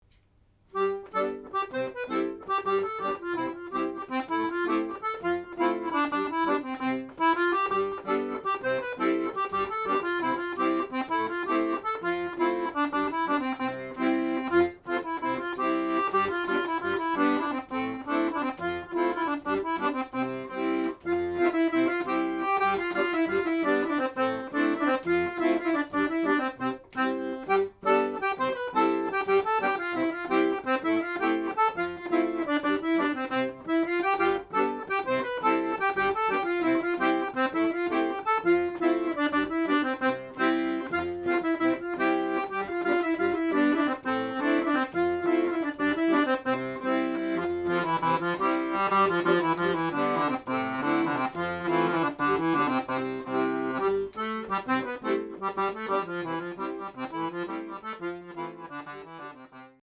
accordeonAccordeon